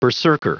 Prononciation du mot berserker en anglais (fichier audio)
Prononciation du mot : berserker